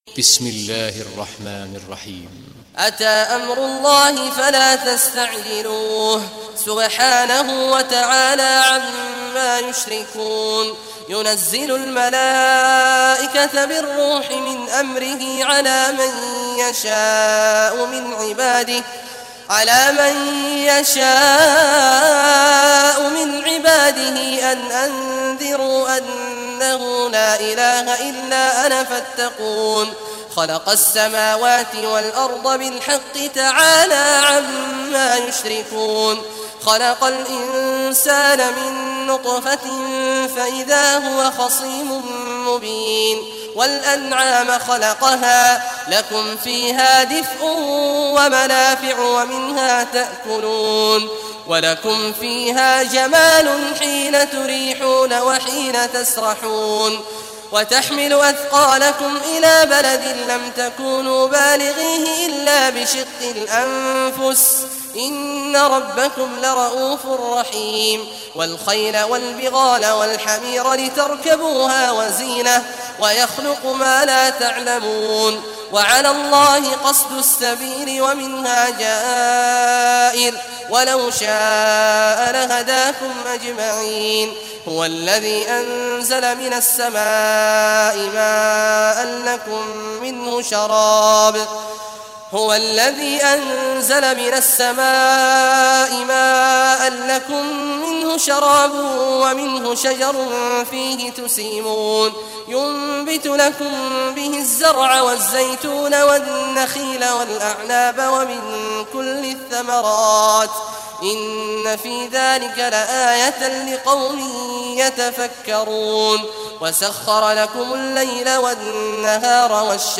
Surah An-Nahl Recitation by Sheikh Abdullah Juhany
Surah Nahl, listen or play online mp3 tilawat / recitation in Arabic in the beautiful voice of Sheikh Abdullah Awad al juhany. Download audio tilawat of Surah An-Nahl free mp3 in best audio quality.